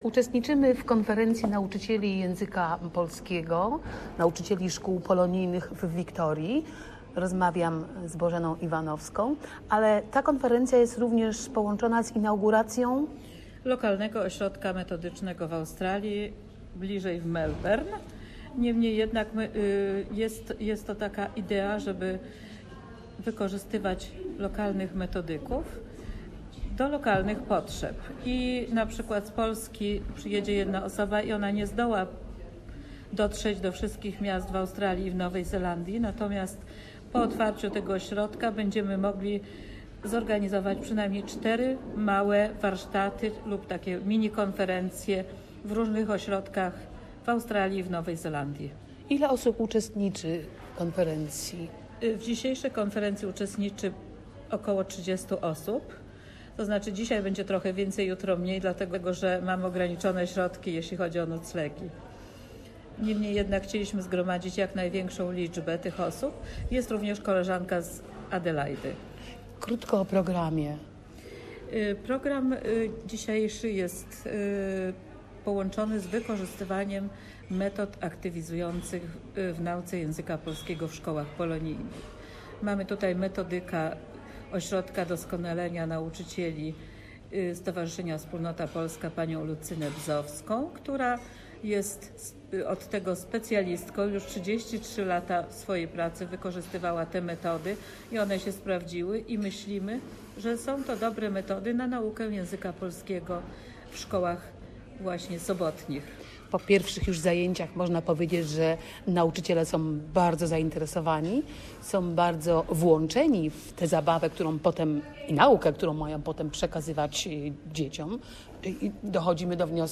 Report from the Polish Teacher's Conference in Balarrat, Victoria.